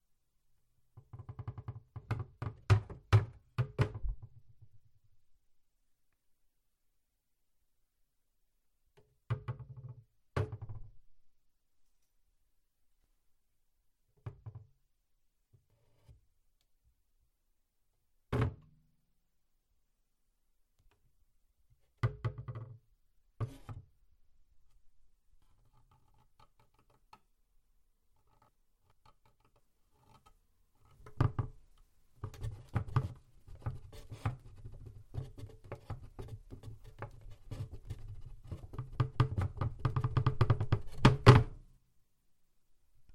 scratching/tapping
cupboard-scratchingtapping.mp3